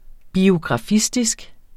Udtale [ biogʁɑˈfisdisg ]